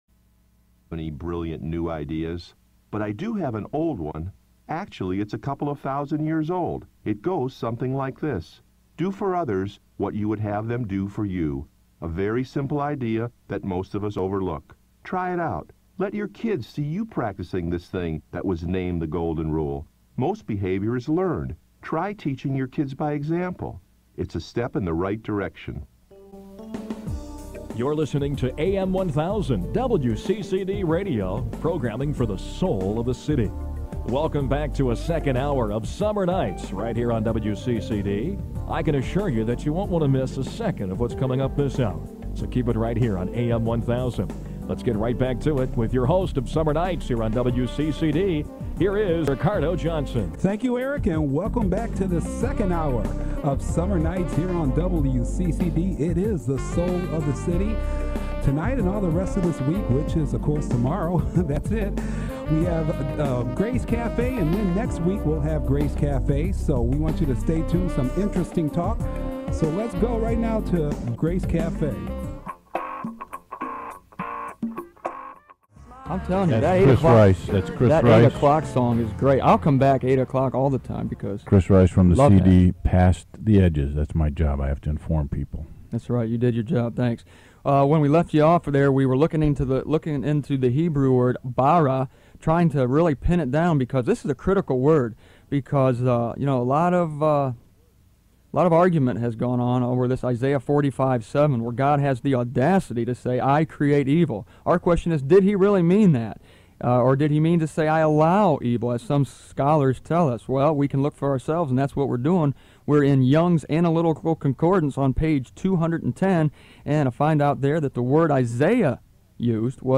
In this audio from the summer of 1999, five other believer and myself went on Christian radio in Cleveland, OH, to explain to anyone who would listen the common-sensical rational behind the purpose of evil—not only the purpose of evil, but its source and future.
All evil will one day be abolished from God's universe, as well as the universal curse of death. Listen to the truth unfold as six broadcasting amateurs dare to believe God's own Words concerning evil: its definition, its entrance into God's universe, and it's ultimate overthrow.